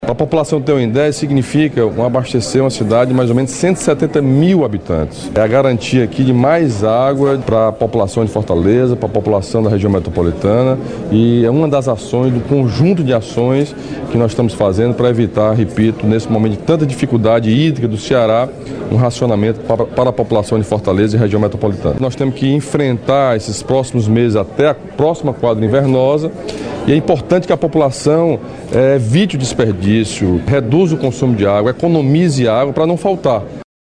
Local: ETA Gavião
Entrevistas:
Governador Camilo Santana